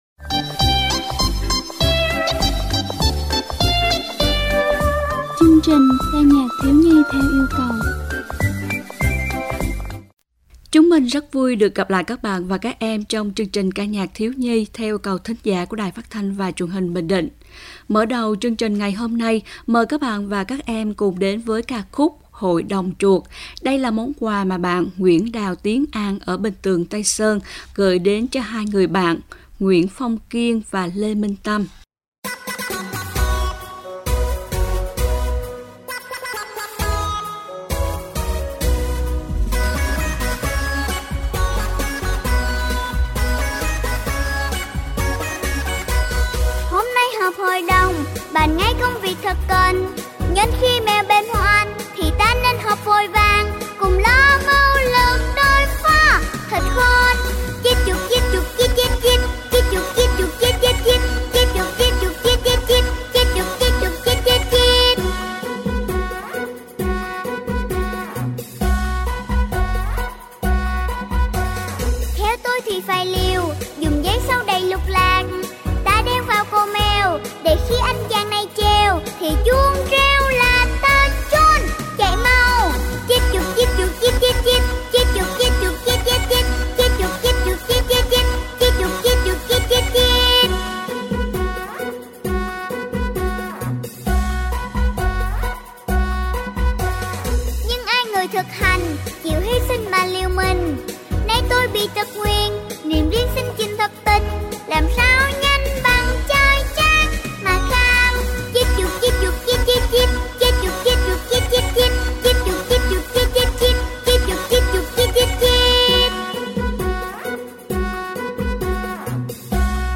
10-12-ca-nhac-thieu-nhi-yeu-cau.mp3